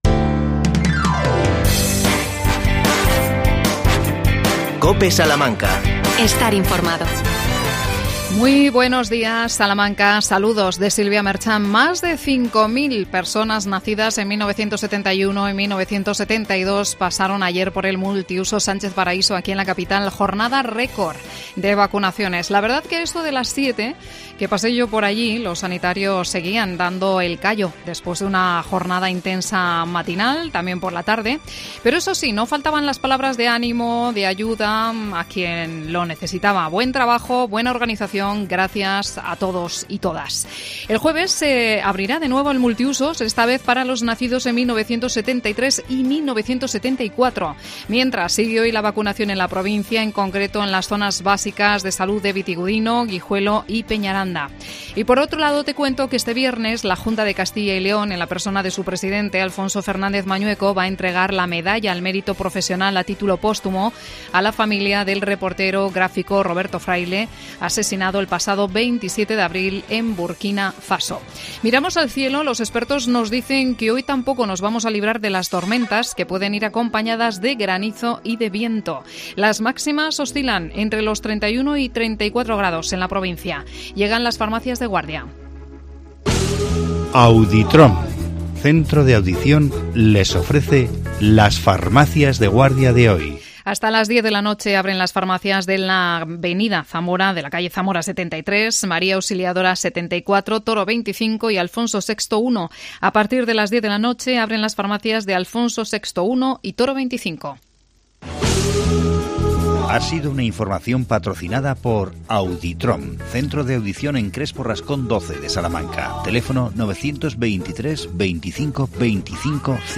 AUDIO: Entrevista al Miriam Rodríguez, concejala de Medio Ambiente. El tema: el Parque Botánico de Huerta Otea.